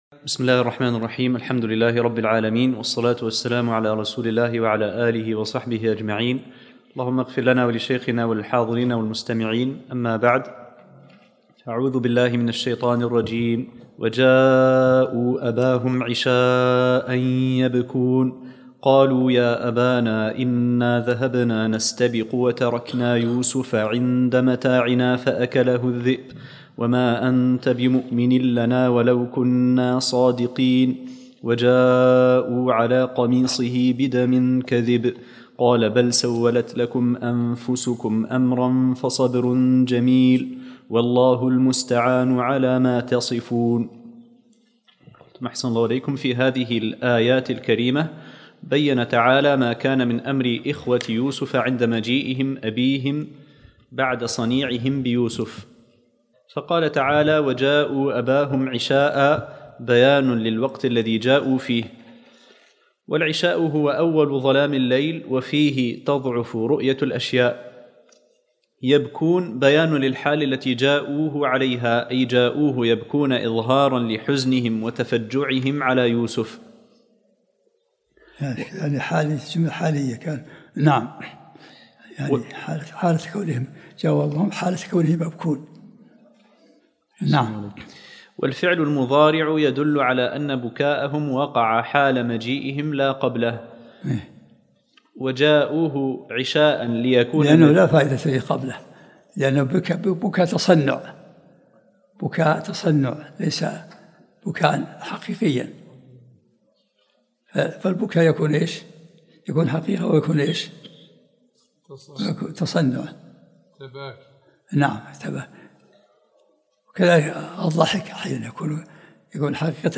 الدرس الرابع من سورة يوسف